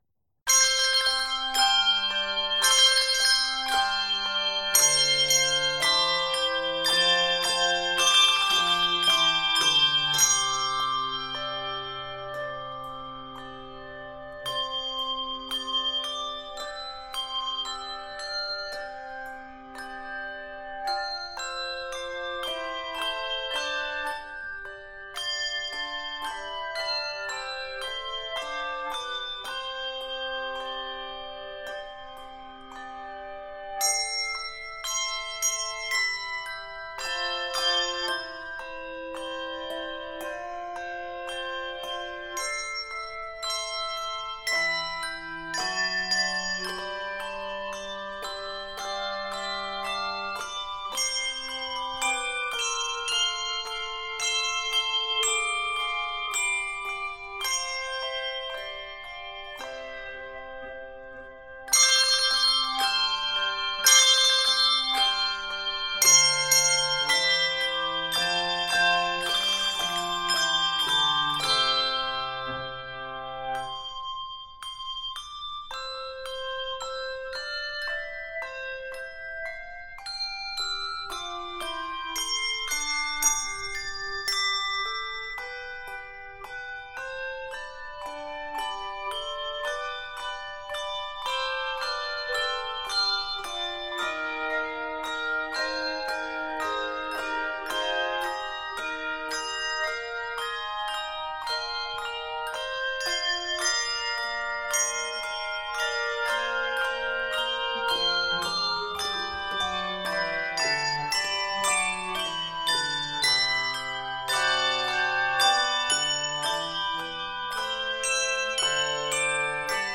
Keys of C Major and Eb Major.